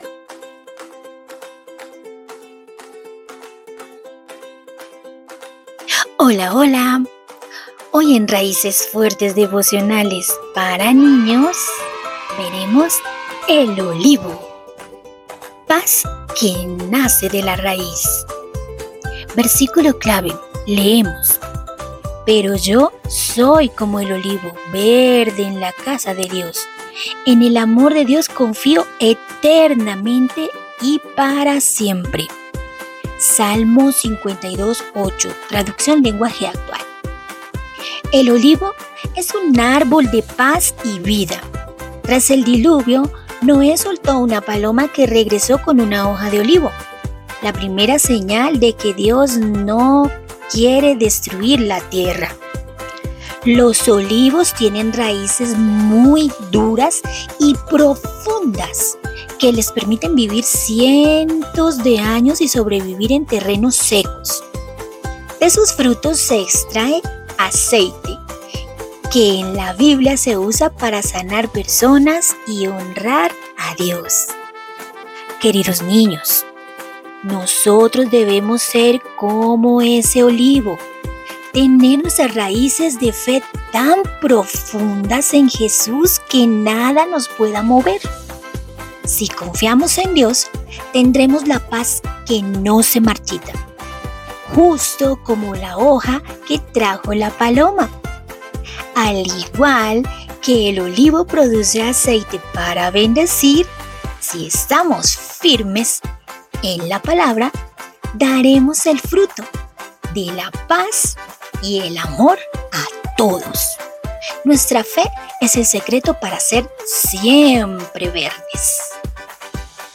Raíces Fuertes – Devocionales para Niños